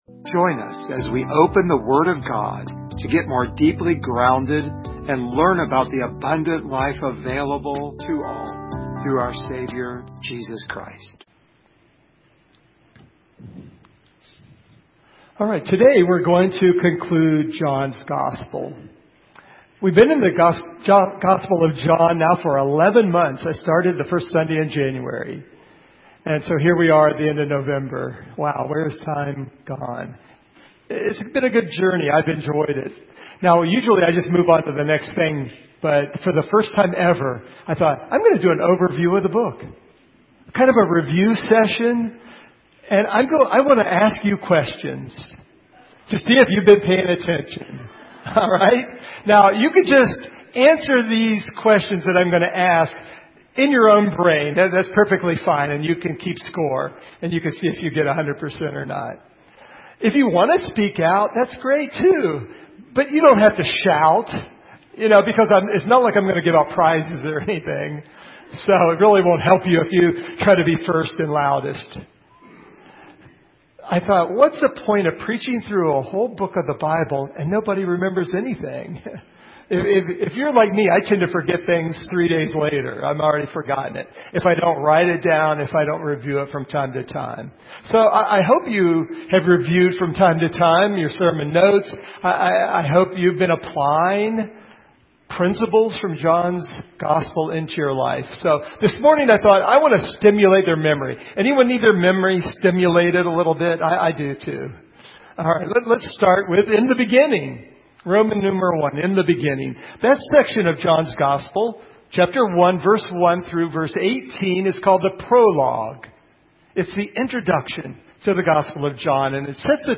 Series: Gospel of John Service Type: Sunday Morning